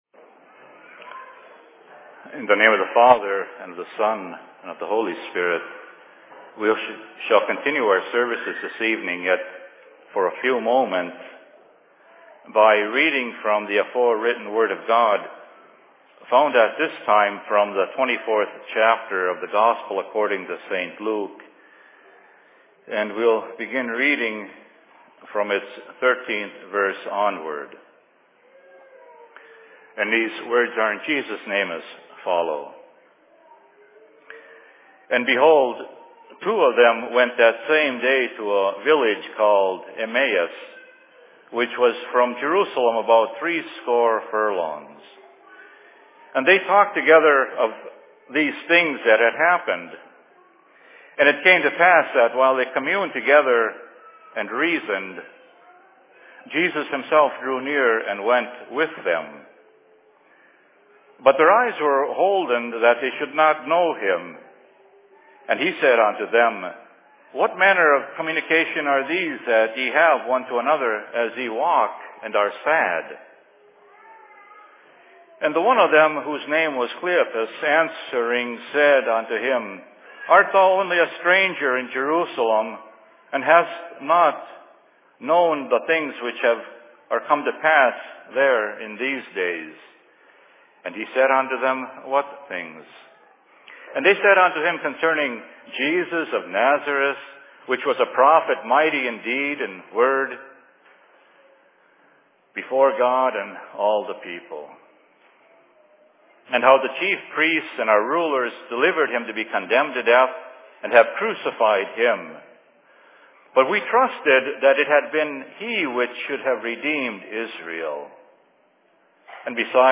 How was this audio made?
Location: LLC Minneapolis